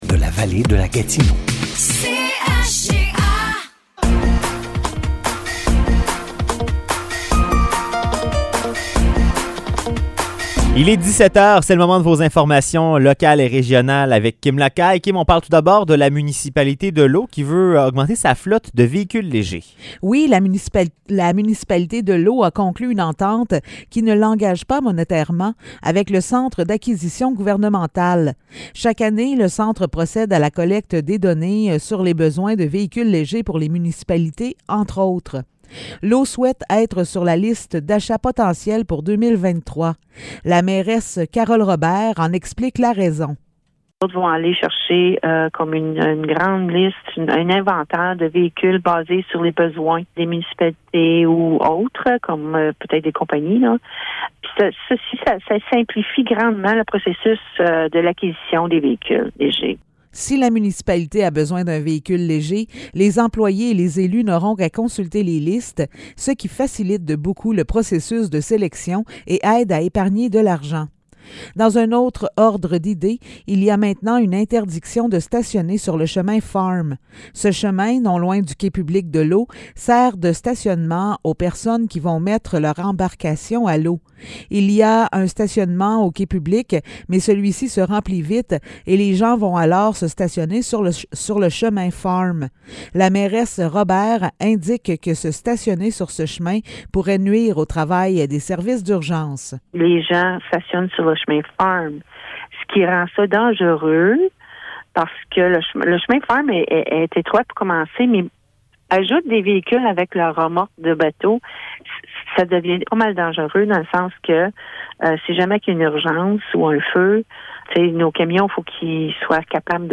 Nouvelles locales - 27 septembre 2022 - 17 h